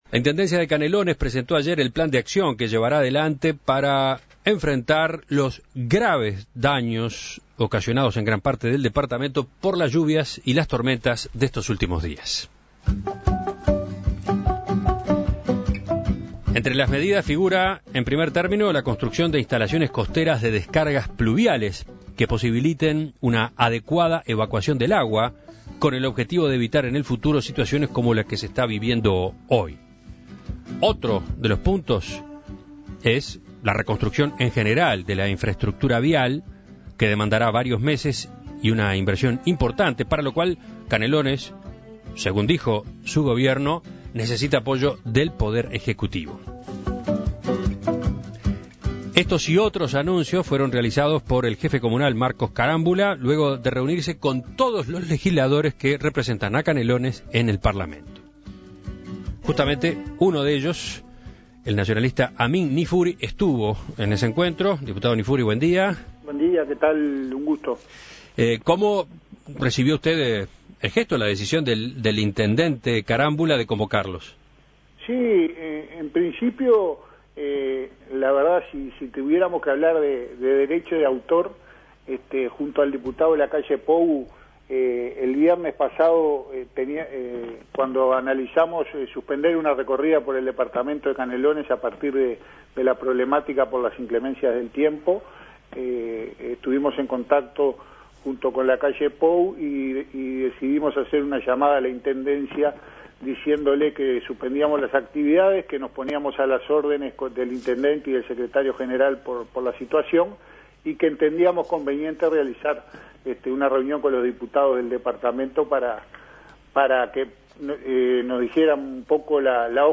En Perspectiva consultó al diputado nacionalista por ese departamento, Amin Niffuri, quien explicó en detalle las zonas de mayor afectación y sus respectivas reparaciones.